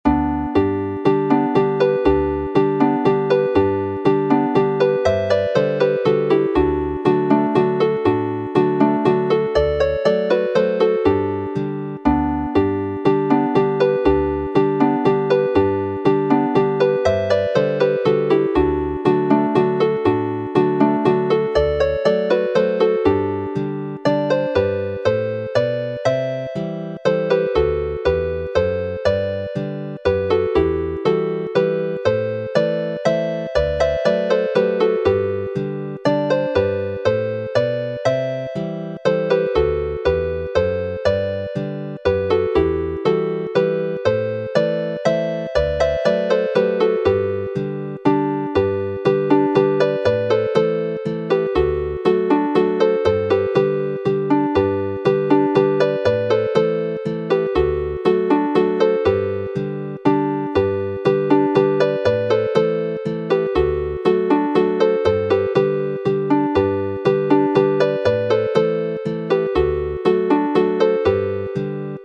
Chwarae'r alaw a'r harmoni